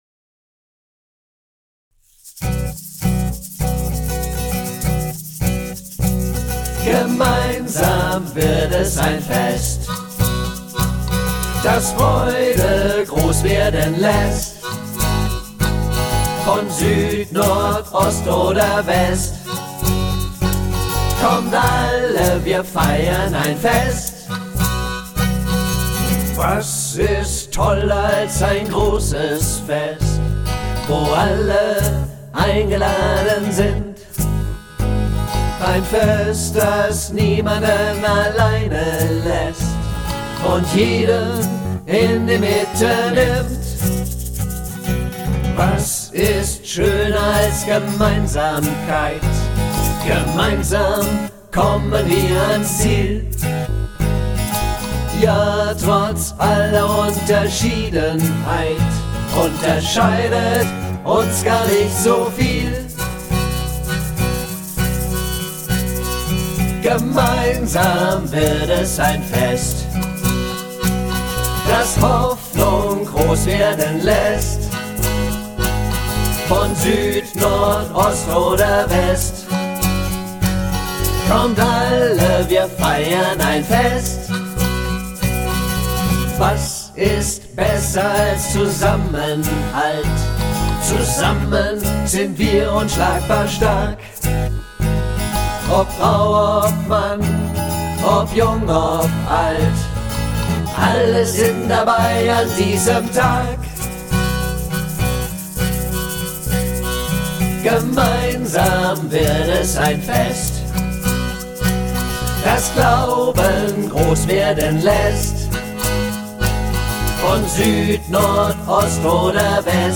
ein fröhliches Lied zum Mitsingen und Mitklatschen!